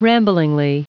Prononciation du mot ramblingly en anglais (fichier audio)
Prononciation du mot : ramblingly